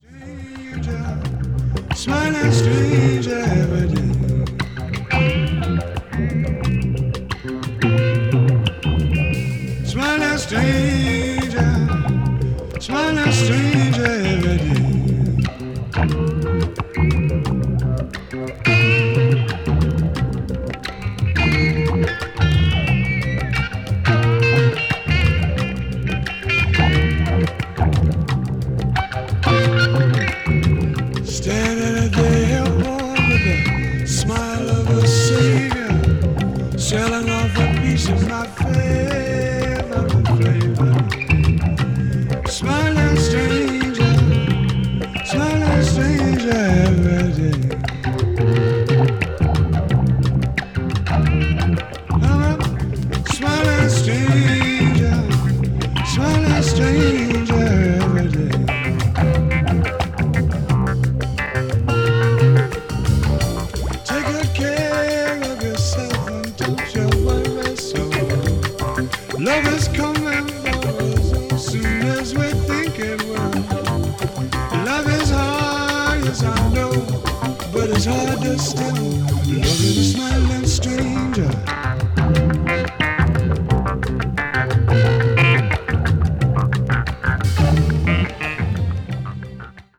a.o.r.   blues rock   mellow groove   mellow rock